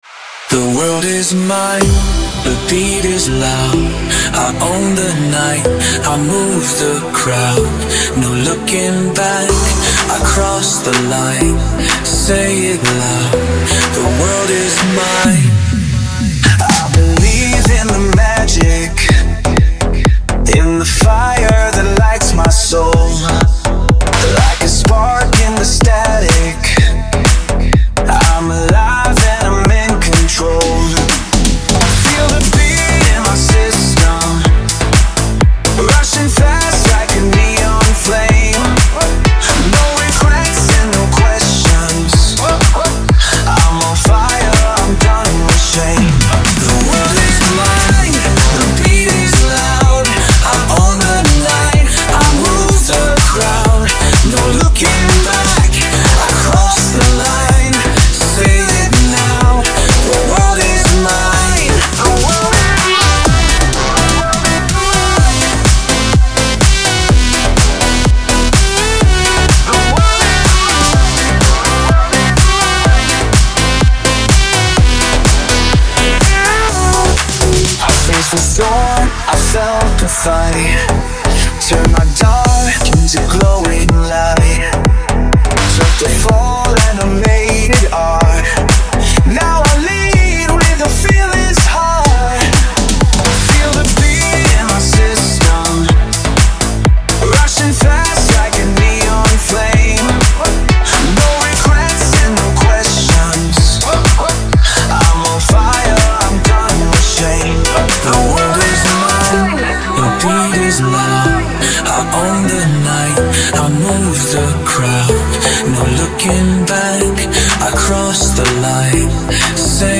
pop , dance